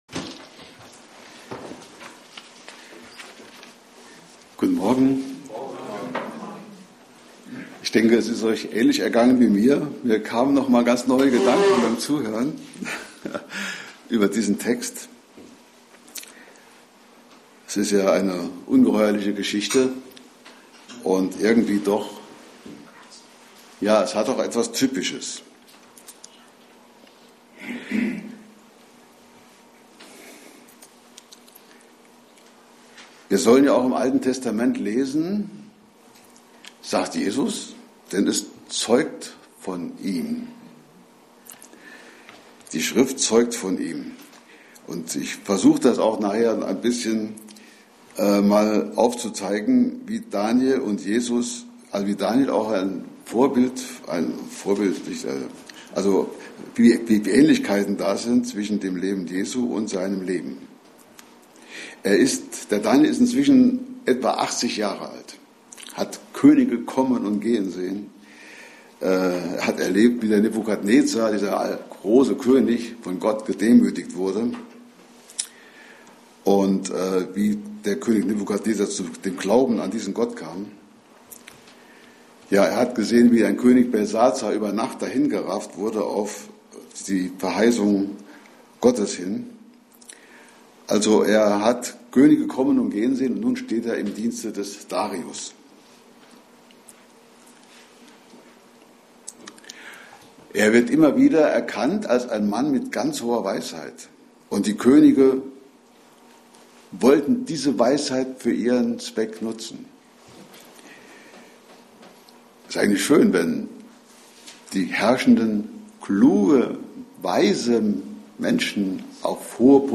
Passage: Jesaja 6,1-8 Dienstart: Predigt